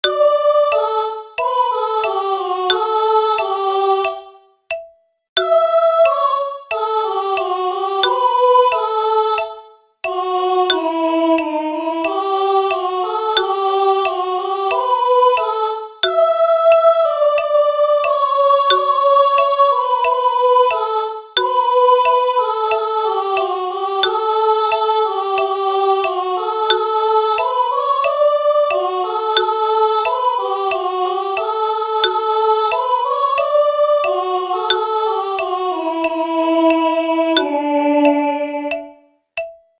Sopran z metronomom
Z_Bogom_Marija_pravi_SOPRAN_..mp3